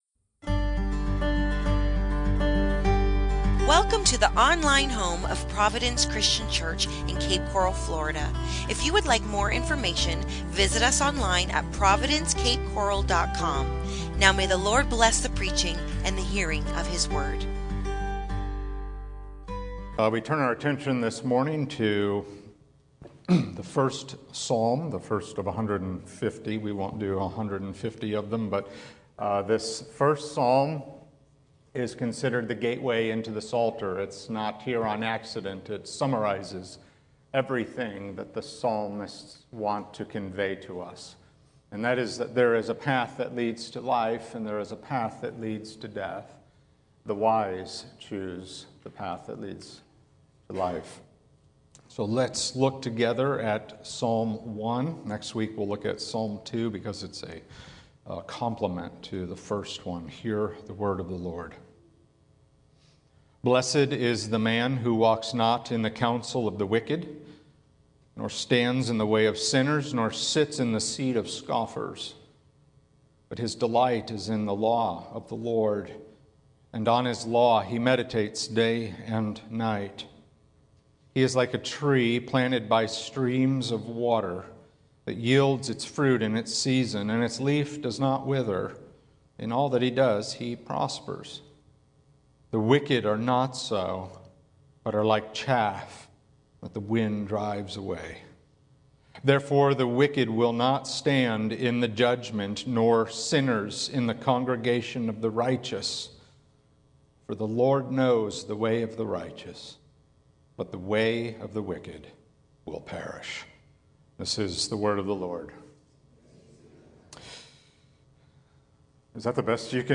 The Walk of Life | SermonAudio Broadcaster is Live View the Live Stream Share this sermon Disabled by adblocker Copy URL Copied!